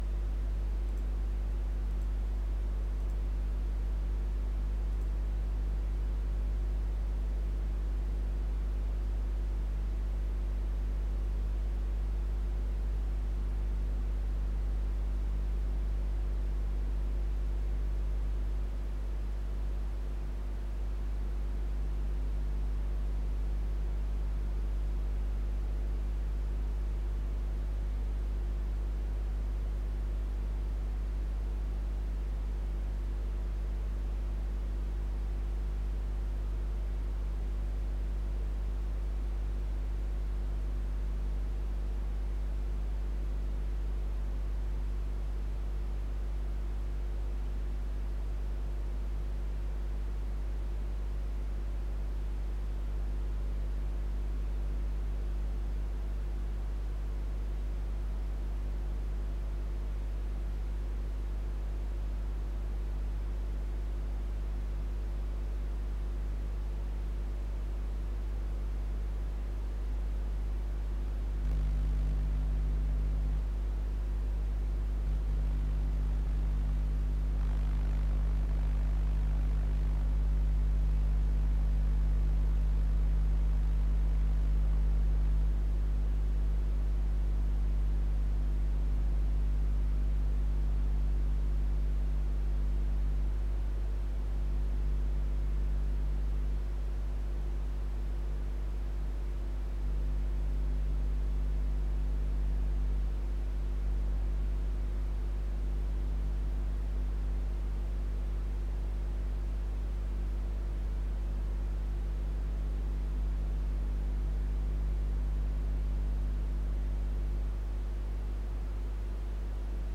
Starkes Rauschen beim Mikrofon (Viel zu stark)
Hab das jetzt auch nochmal mit Audacity aufgenommen und als Datei angehängt, da hört man das zwar nicht immer raus, aber ca ab eine Minute wird es lauter und zwischendurch ist so ein Brummen zu hören. (Brummen nur einmal) Jetzt weiß ich nicht, ob das Mikro den Rechner mit aufgenommen hat oder ob das alles von der Grafikkarte kam.